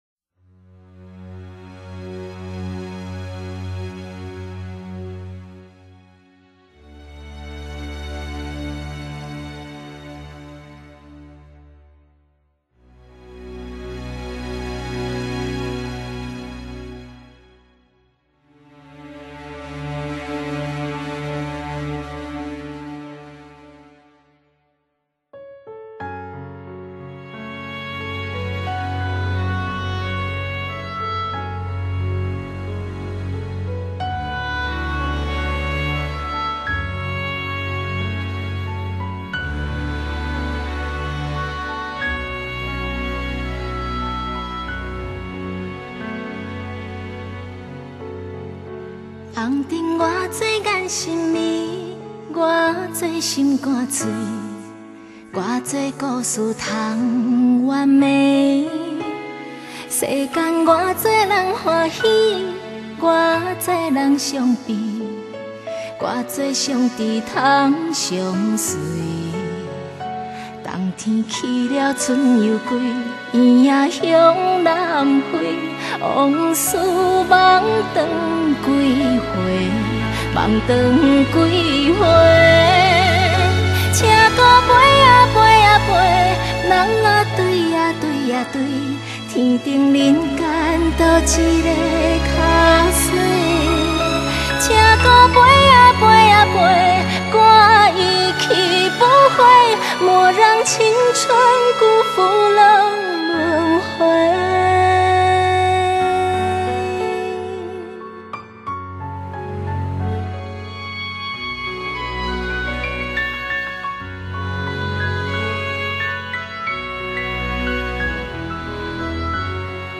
動人的聲線 情感的加持
弦樂及輕鋼琴 讓歌詞的意境融入淒美的曲風中 使整首歌的故事更為動人